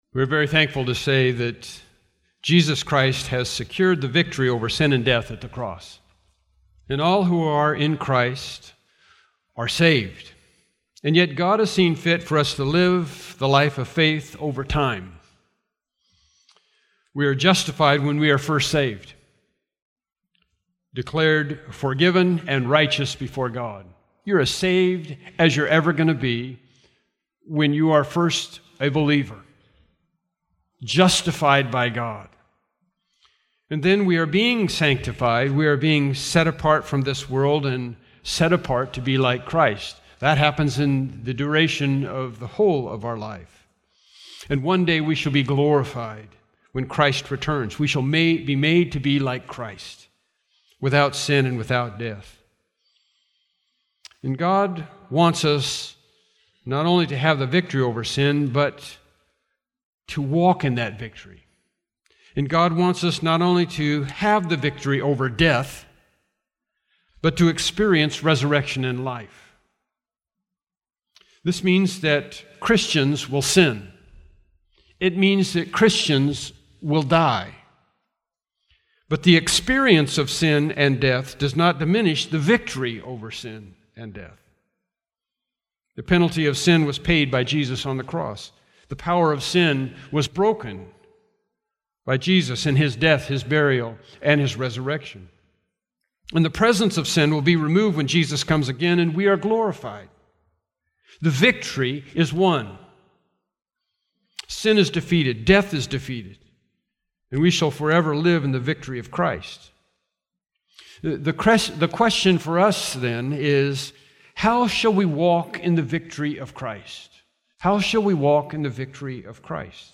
Bible Text: John 21:18-19 | Preacher